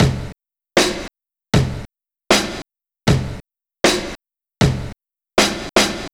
Track 11 - Kick Snare Beat 04.wav